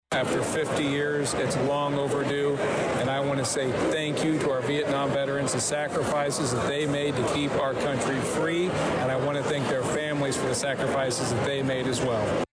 Saturday’s ceremony at Georgetown-Ridge Farm High School was moved indoors due to the wet weather from Friday.